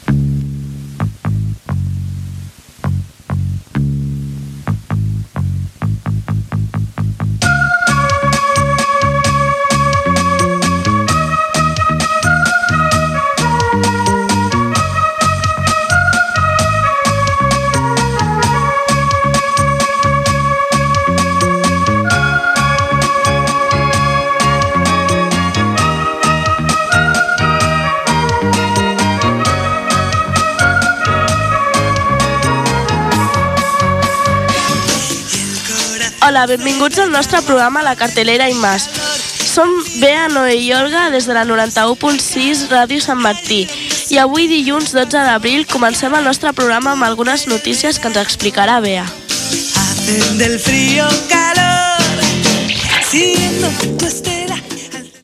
029dd4f64f46608d5bbbf55395c97b148bf3cfe3.mp3 Títol Ràdio Sant Martí Emissora Ràdio Sant Martí Titularitat Tercer sector Tercer sector Escolar Nom programa La cartelera y más Descripció Música identificativa de l'emissora, identificació, data i salutació inicial.